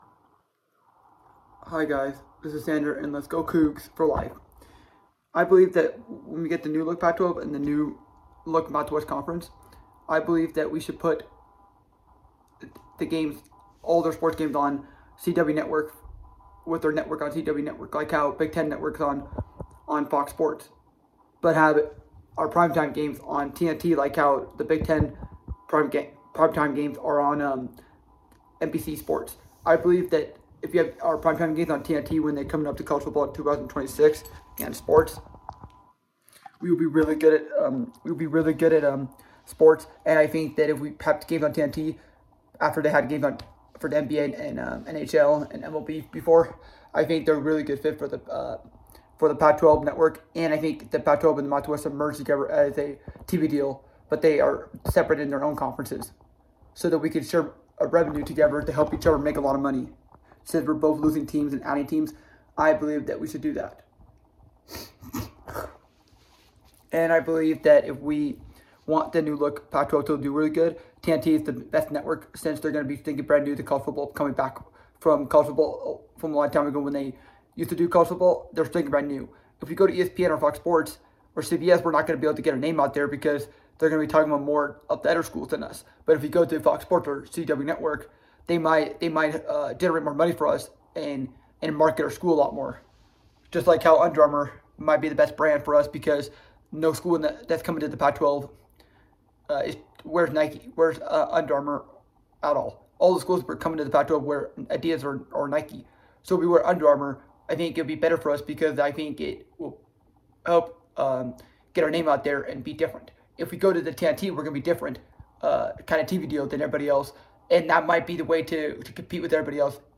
Commentary: Who I think Pac-12 should add